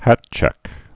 (hătchĕk)